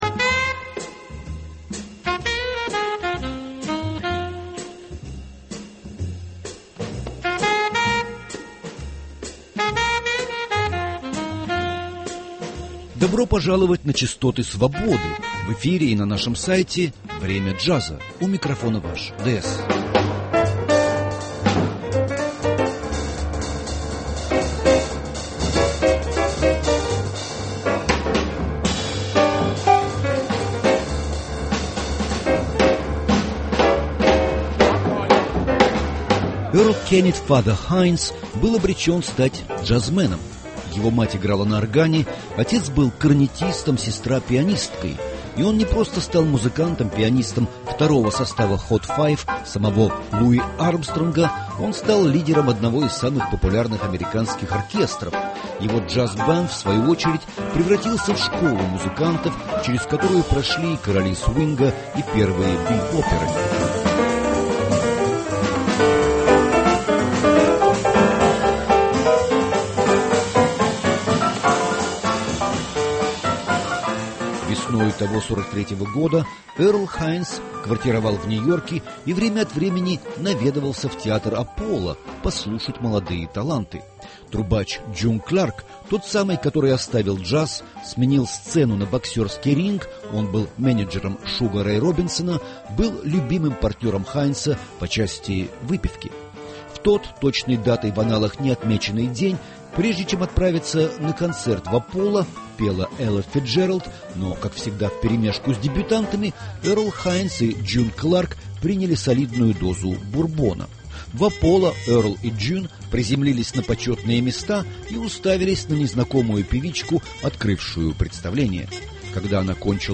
Сара Вон была не только великолепной певицей, чье сопрано звучало, как саксофон, она была и чудесной пианисткой.